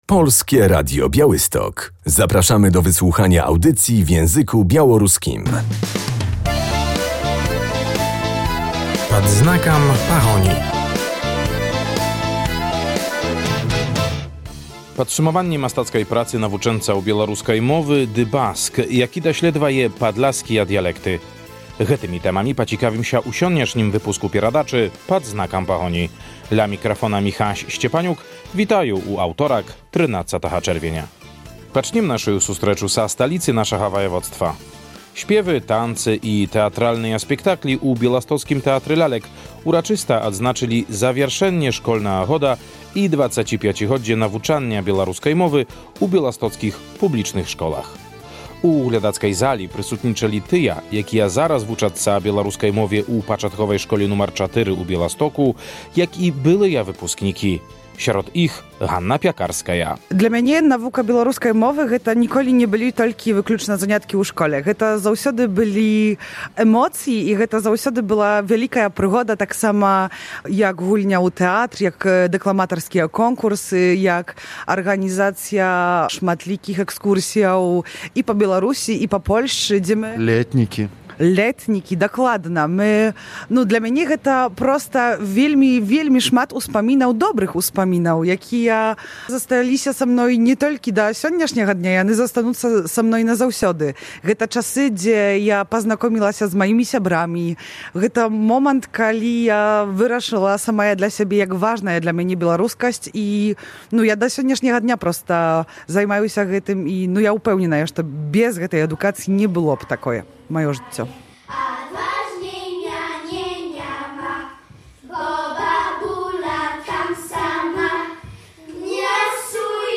W Białostockim Teatrze Lalek odbyło się podsumowanie artystycznej pracy dzieci i młodzieży uczących się języka białoruskiego w Białymstoku.